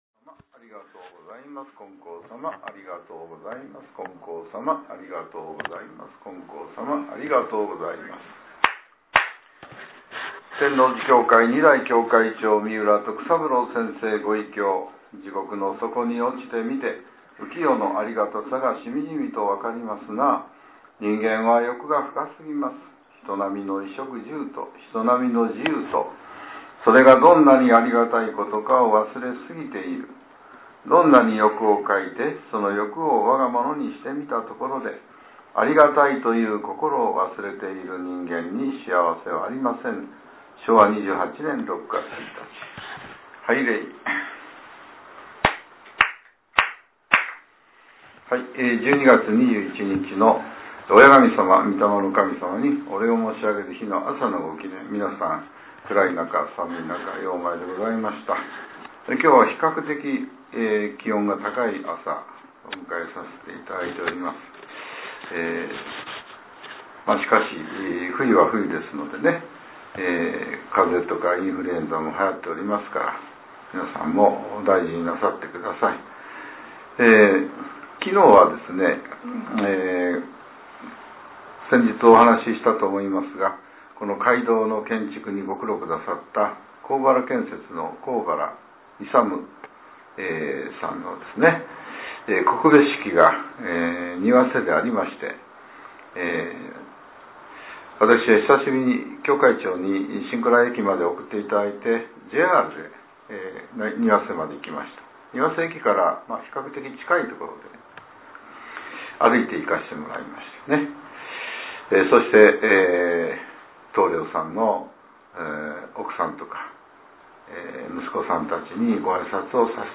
令和７年１２月２１日（朝）のお話が、音声ブログとして更新させれています。